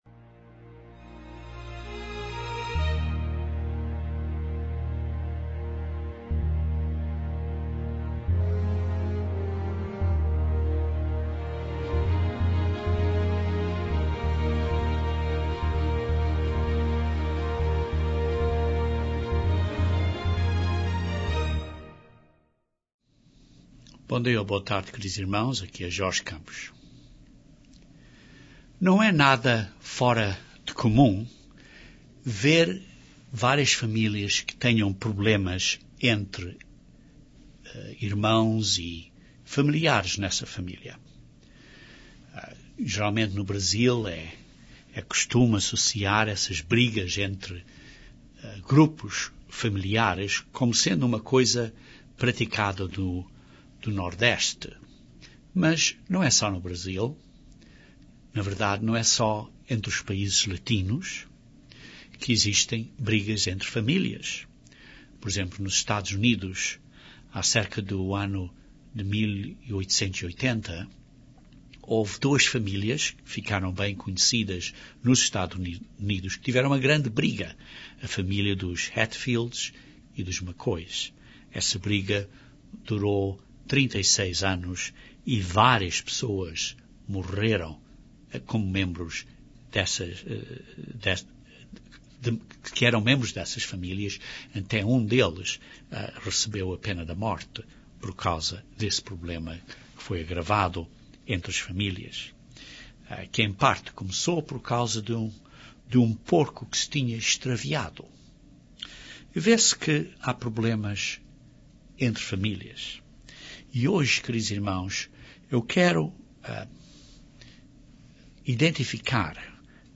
Este sermão descreve 3 princípios importantes que ajudarão a resolver e/ou evitar a ter problemas em muitas situações entre pessoas, amigos, famílias e nações.